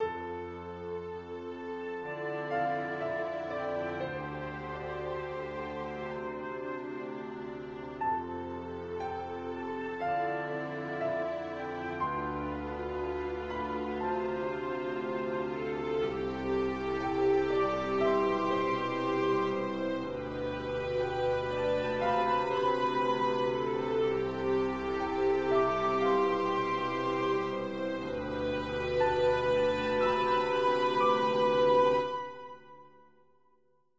描述：四弦琴的和弦音乐。可爱快乐循环。
标签： 乐器 可爱 循环 背景音乐 快乐 四弦琴 简单 音乐
声道立体声